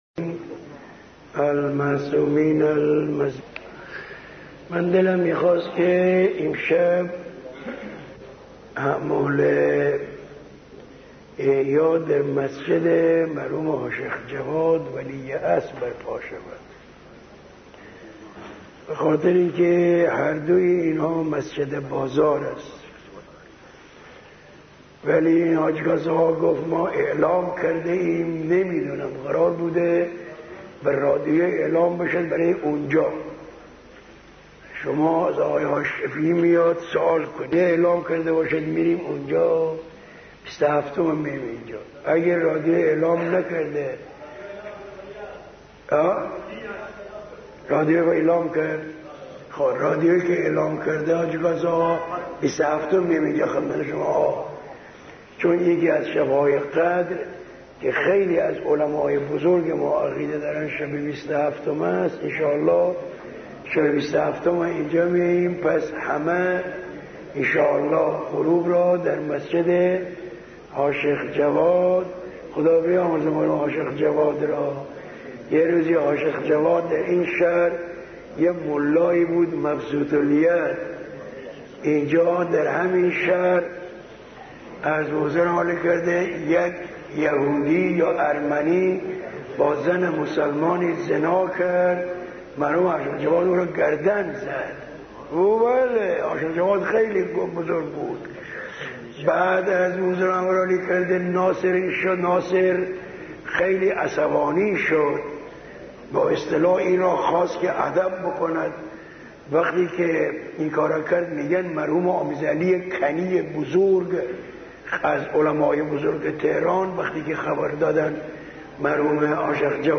صوت(7) سخنرانی حضرت روحانی شهید حاج صادق احسان‌بخش